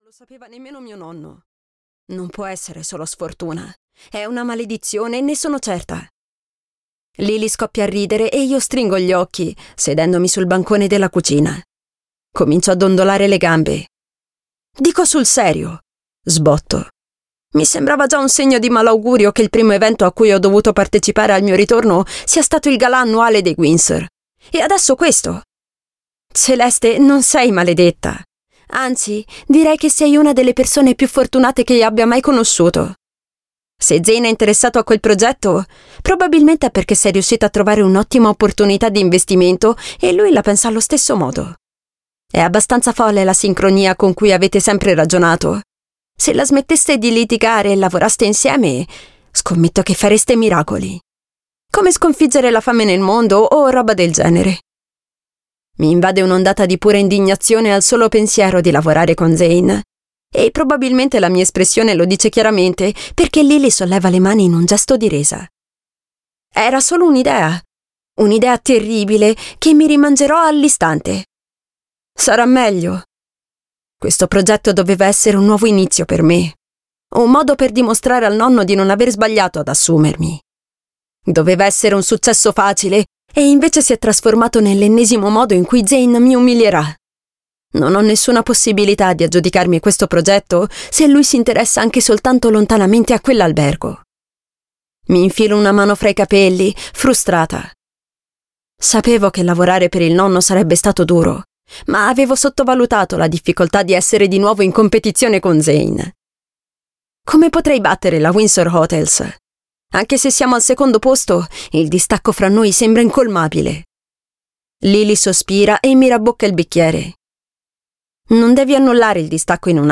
"Promesse infrante" di Catharina Maura - Audiolibro digitale - AUDIOLIBRI LIQUIDI - Il Libraio